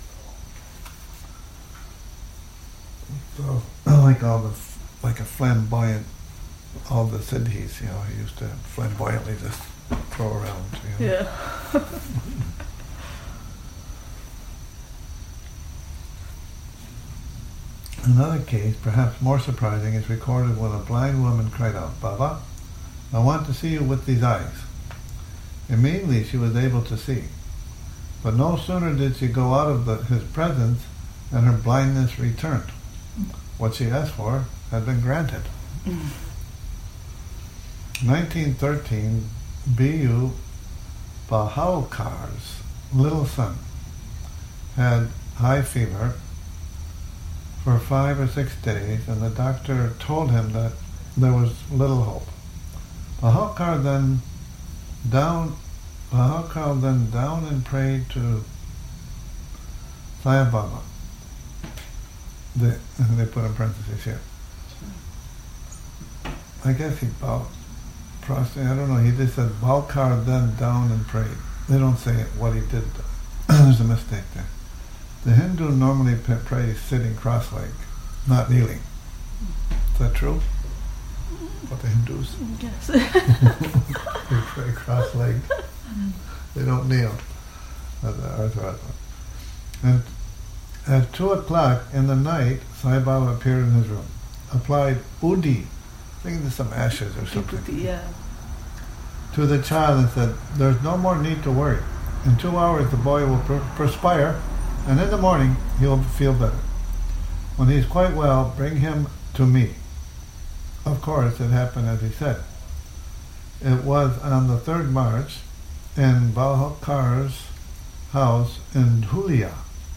Morning Reading, 24 Sep 2019`